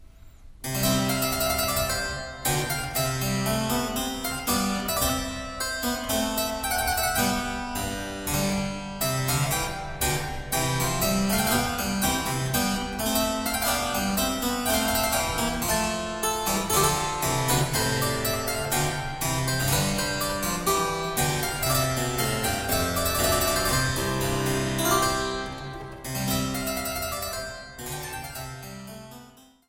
Virginal und Cembalo